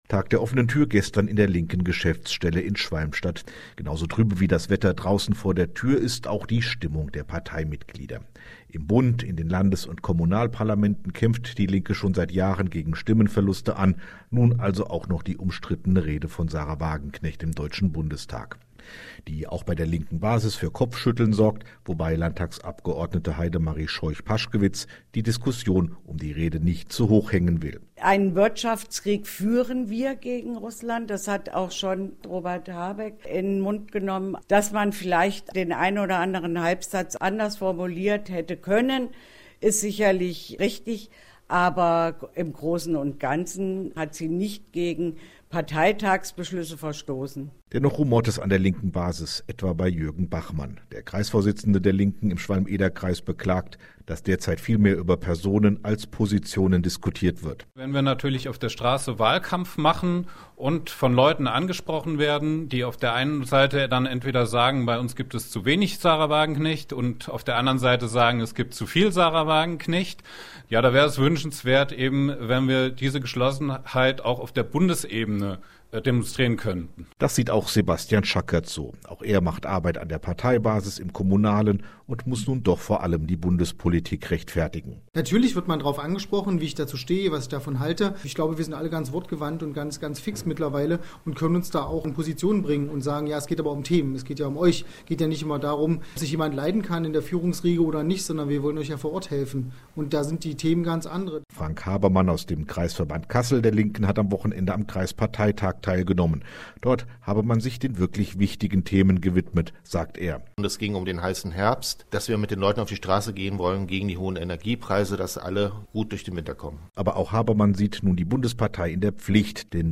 Zusätzlich wurde unsere Aktion zum heißen Herbst vom HR Inforadio mit Fragen zum aktuellen Zustand der Bundestagsfraktion begleitet.